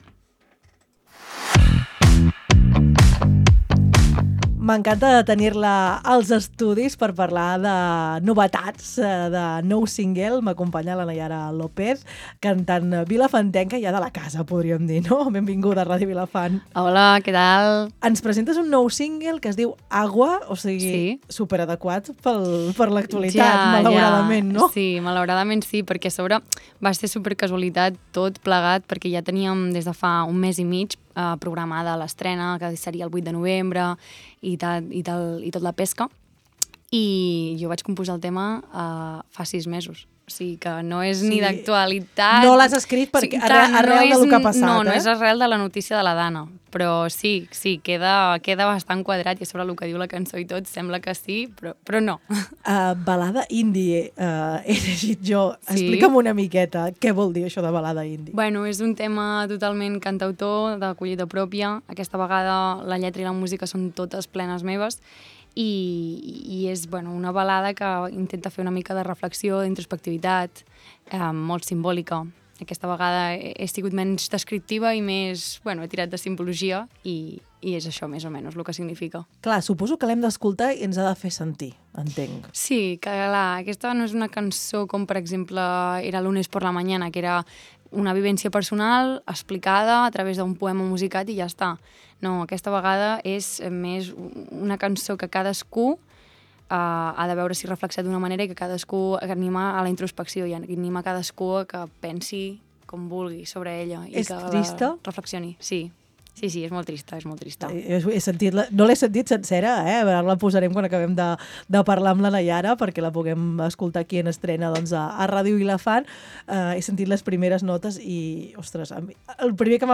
N’hem parlat amb ella als estudis de Ràdio Vilafant.